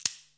shaker8.wav